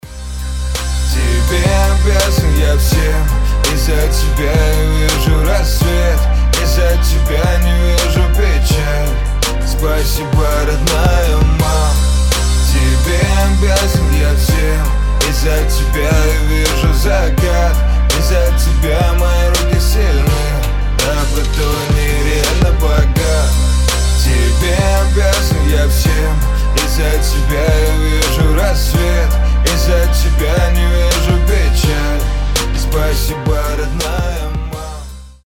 • Качество: 320, Stereo
душевные
русский рэп
лирические